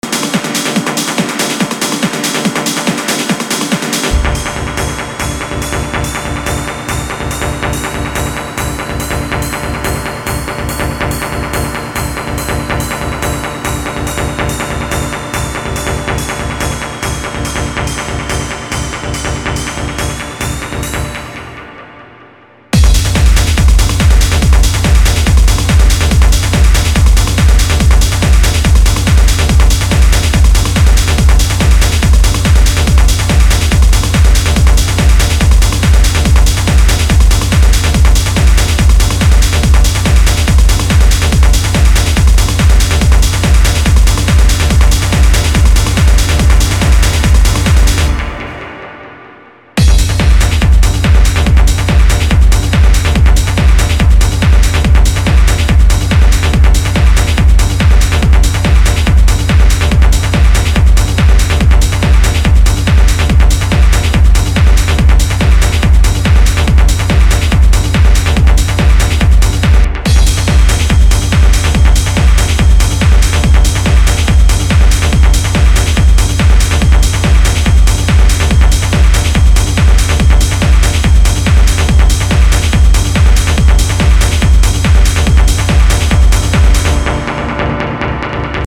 Techno Dub Techno